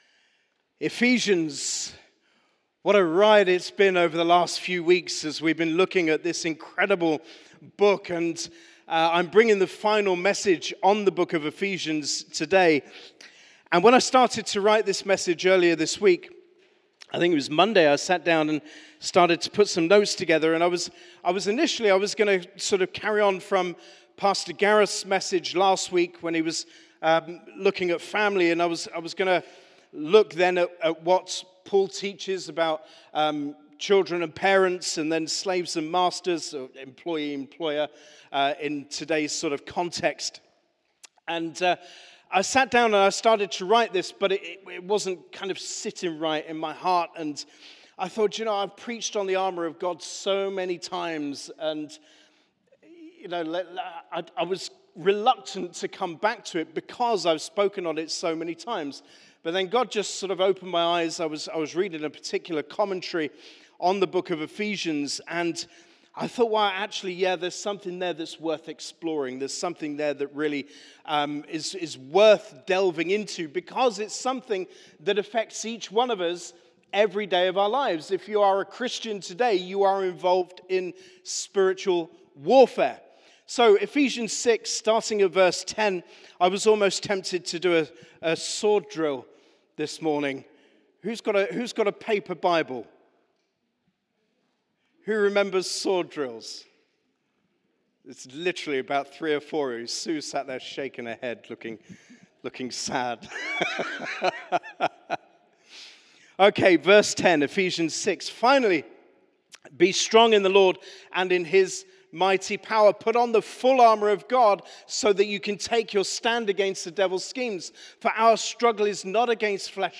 Sermon - Ephesians 6: 10-20 'The Armour of God'